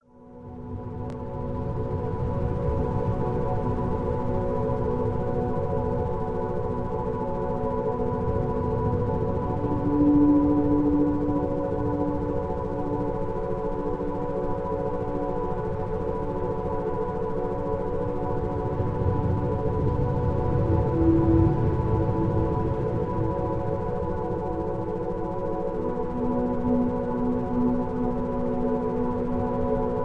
Types of Entrainment Sounds: Isochronic Beat
Background Sounds/Environment: Music & Sound of Wind
Frequency Level: Theta, Alpha & Beta